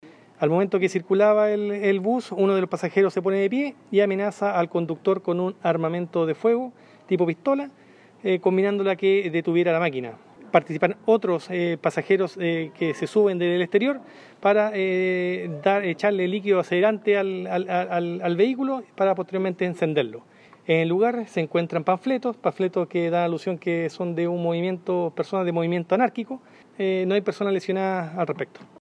cuna-bus-carabinero.mp3